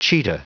Prononciation du mot cheetah en anglais (fichier audio)
Prononciation du mot : cheetah